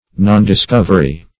Search Result for " nondiscovery" : The Collaborative International Dictionary of English v.0.48: Nondiscovery \Non`dis*cov"er*y\, n. Want or failure of discovery.
nondiscovery.mp3